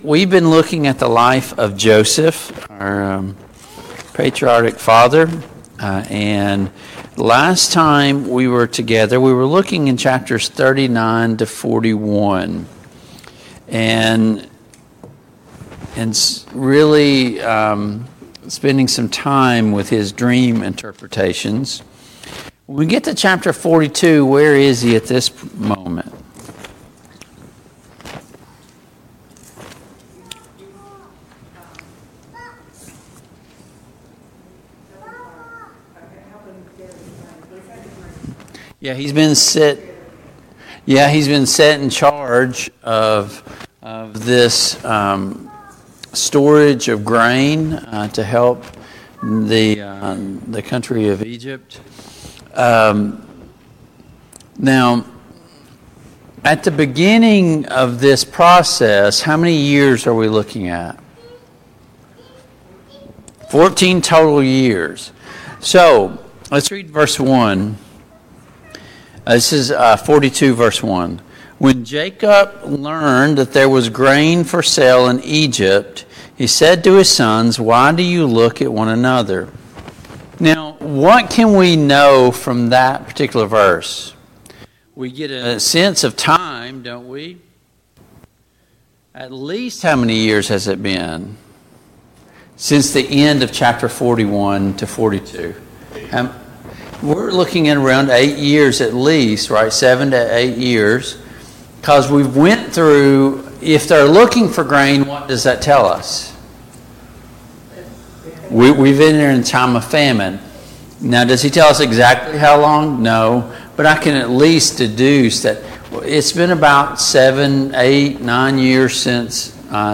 Genesis 42 Service Type: Family Bible Hour Topics: Joseph and his brothers « How can I know that I’m saved?